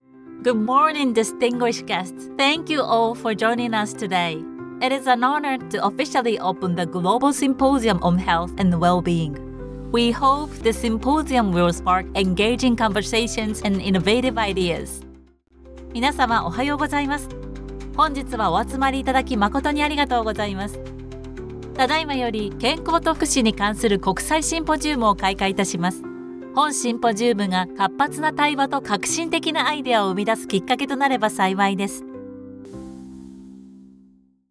司会のボイスサンプルです。
シンポジウム開会アナウンス（英・日）
host-symposium.mp3